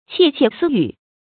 切切私语 qiè qiè sī yǔ 成语解释 指私下小声说话。